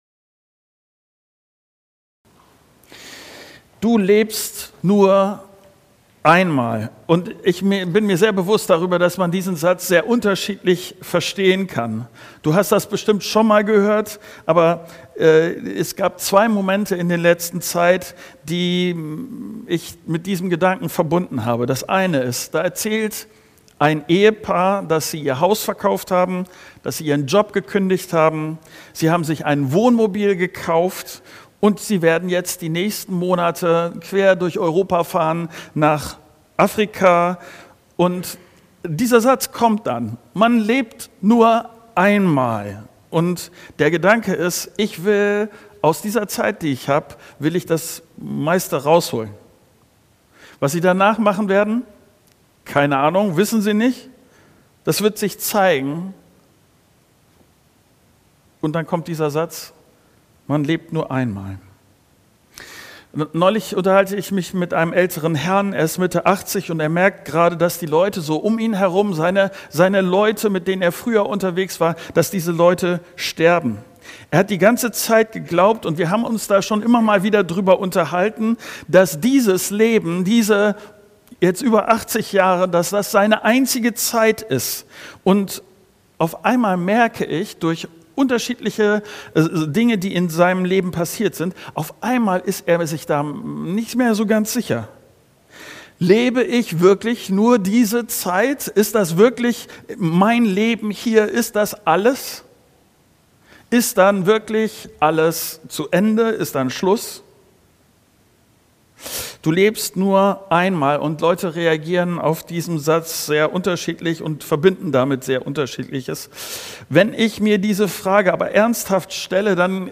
19.10.2025 - Du lebst nur einmal ~ Predigten der Christus-Gemeinde | Audio-Podcast Podcast